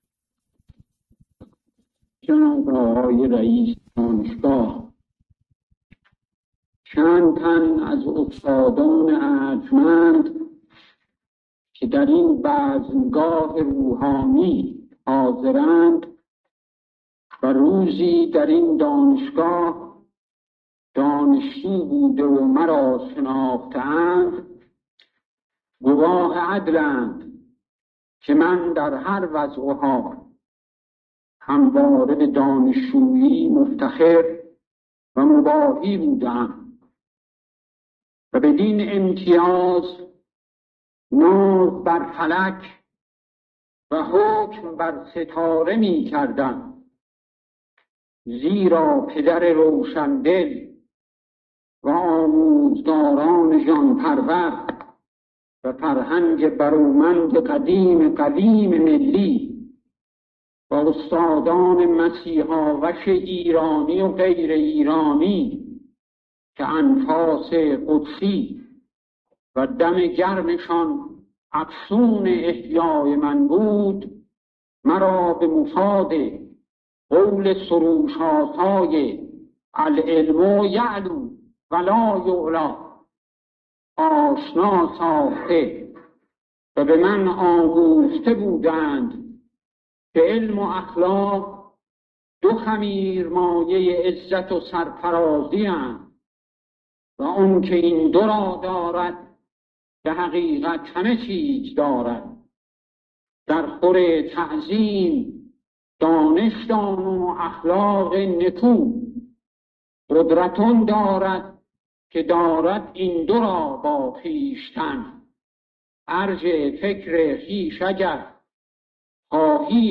در سایه خدمات یک استاد ممتاز؛ انتشار خطابه دکتر صدیقی در مراسم دریافت درجه استاد ممتازی دانشگاه تهران